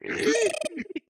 Minecraft Version Minecraft Version snapshot Latest Release | Latest Snapshot snapshot / assets / minecraft / sounds / mob / strider / happy2.ogg Compare With Compare With Latest Release | Latest Snapshot